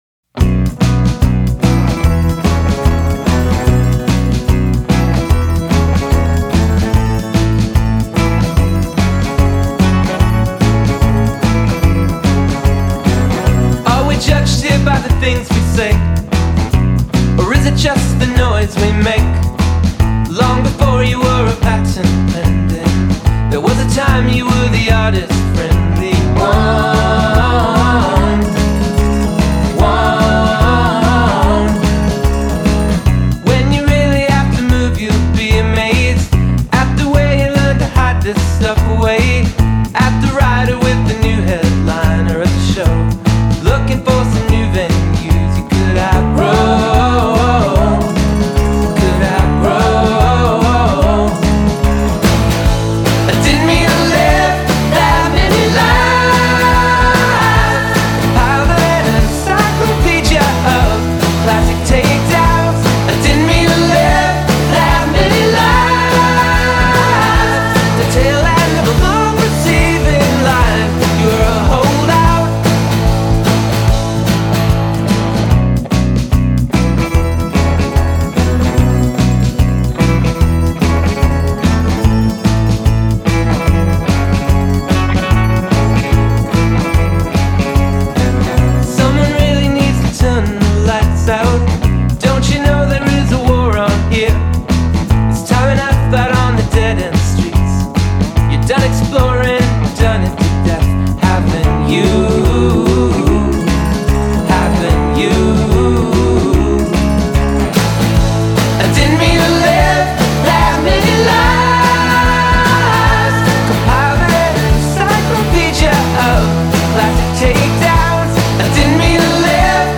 buzzes with a Danger Mouse-esque production